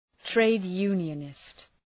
Προφορά
{,treıd’ju:njənıst} (Ουσιαστικό) ● συνδικαλιστής